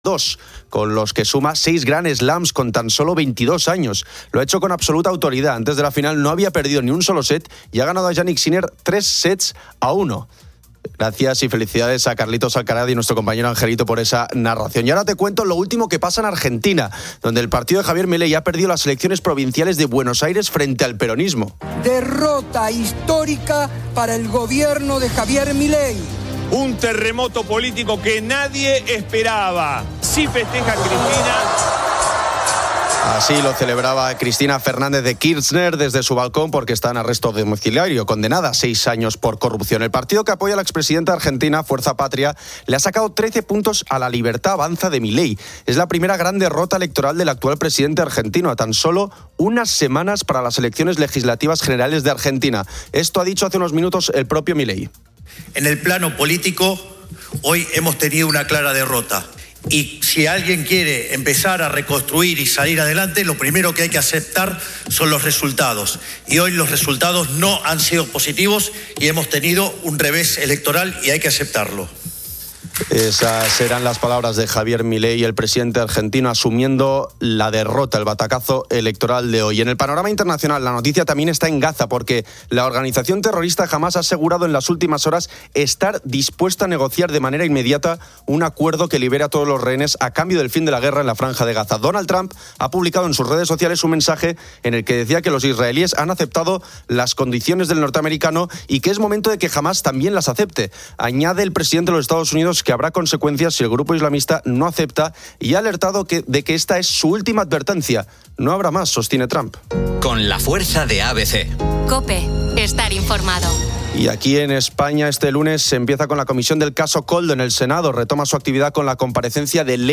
También se incluyó la sección "Ponedores de Calles" con oyentes compartiendo sus variadas profesiones.